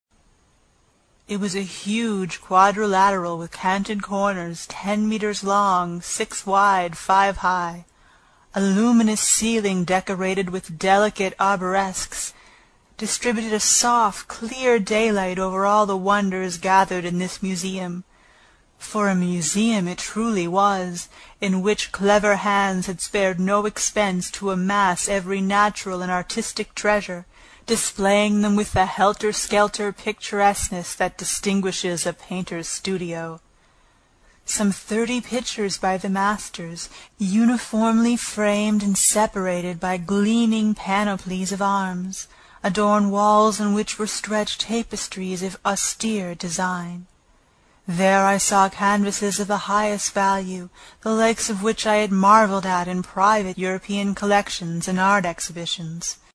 英语听书《海底两万里》第156期 第11章 诺第留斯号(7) 听力文件下载—在线英语听力室
在线英语听力室英语听书《海底两万里》第156期 第11章 诺第留斯号(7)的听力文件下载,《海底两万里》中英双语有声读物附MP3下载